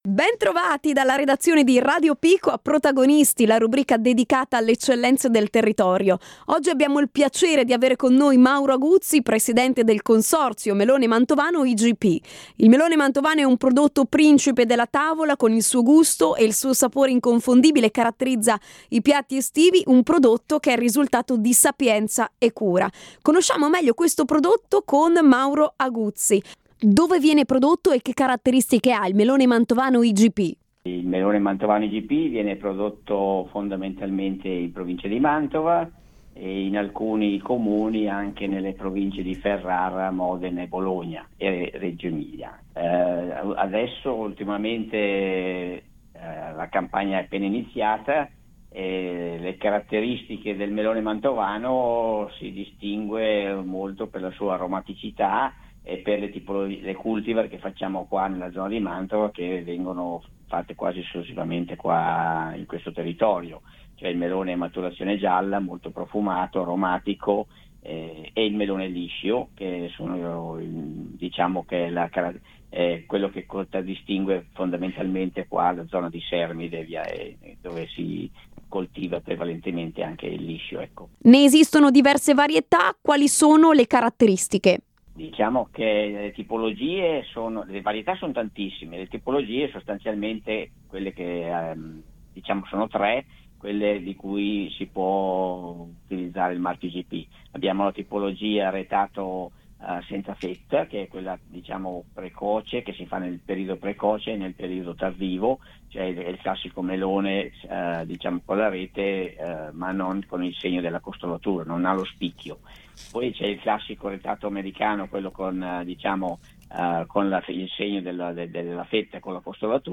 Radio Pico intervista
Ai nostri microfoni